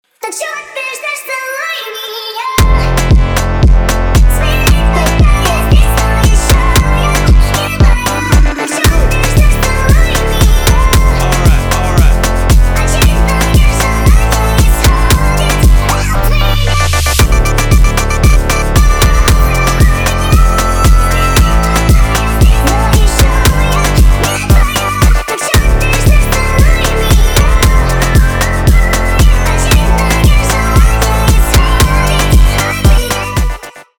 поп
фонк , битовые , басы , качающие